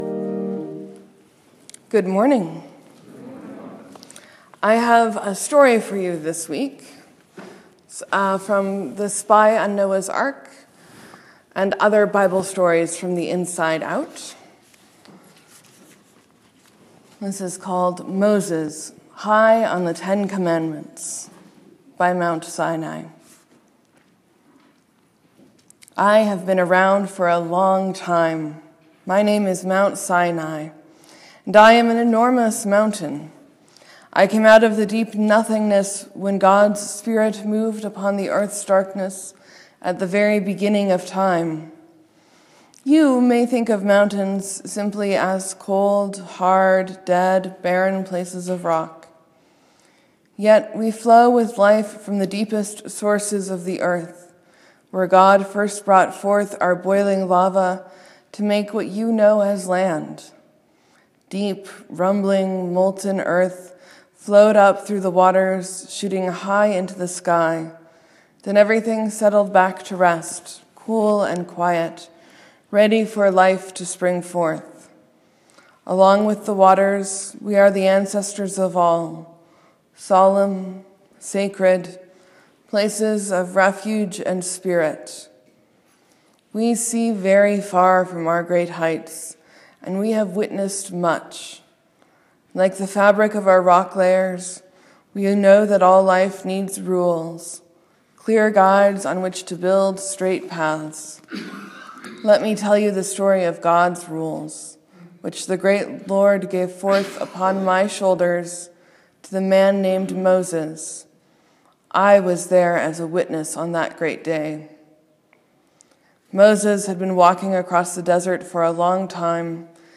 Sermon: Paul seems to advocate for vegetarianism and I talk about Martin Luther.